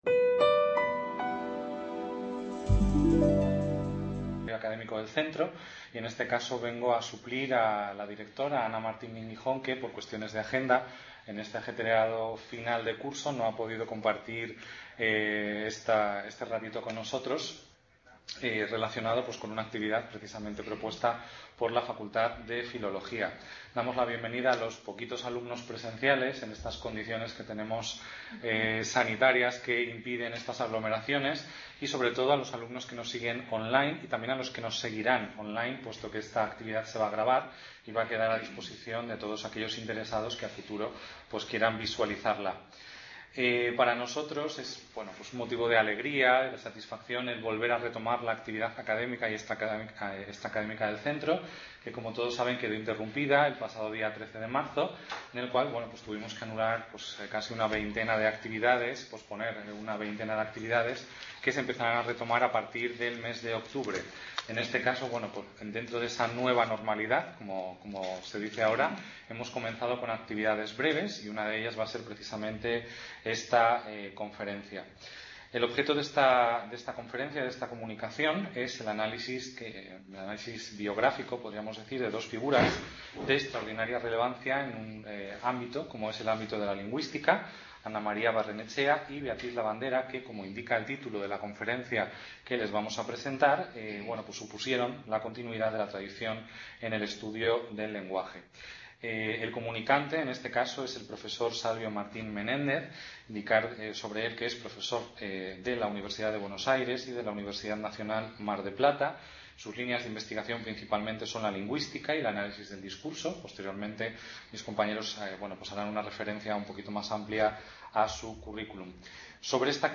conferencia